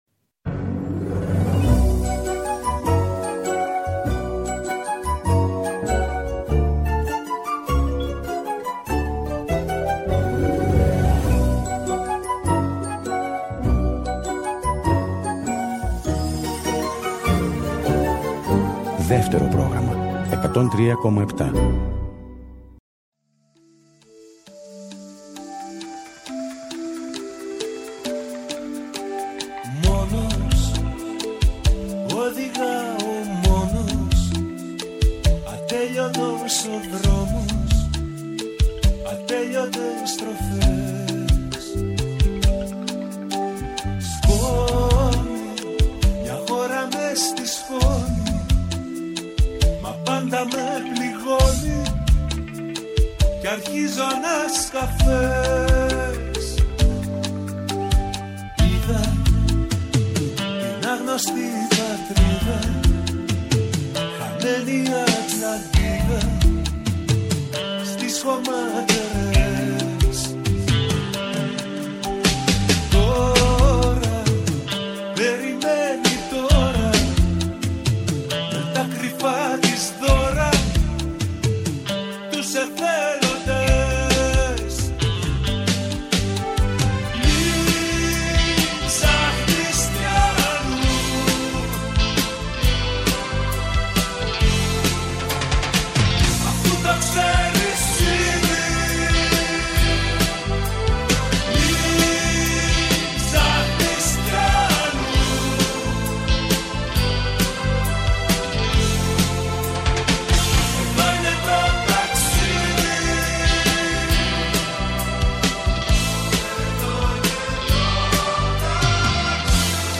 Σε αυτή τη νέα ραδιοφωνική εκπομπή
επιλεγεί τραγούδια που ακούστηκαν στο τηλεοπτικό «Μουσικό Κουτί»